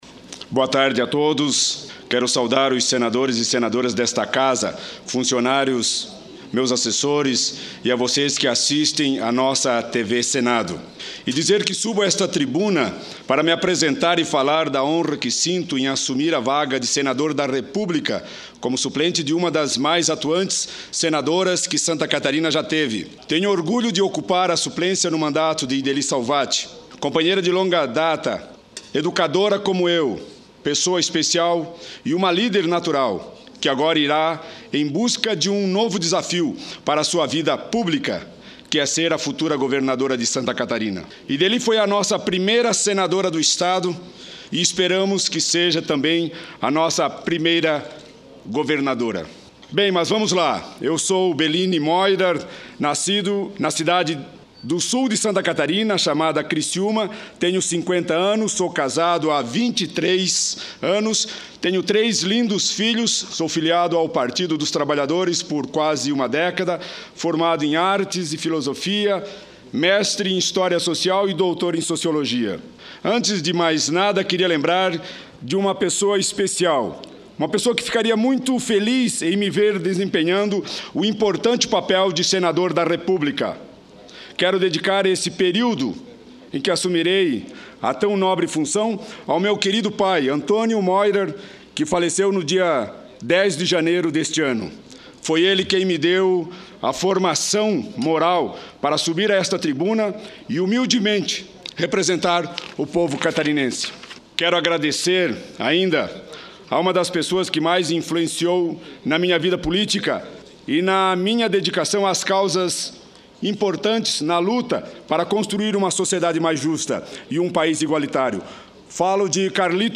Bellini Meurer (PT-SC) faz discurso de posse